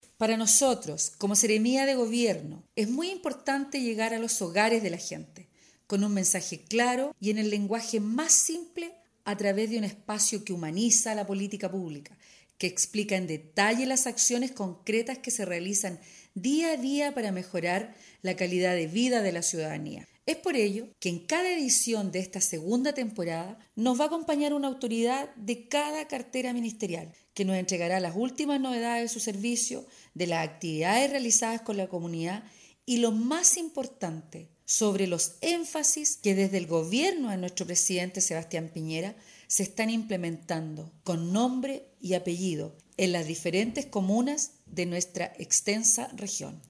CUÑA-1-SEREMI-DE-GOBIERNO-INGRID-SCHETTINO..mp3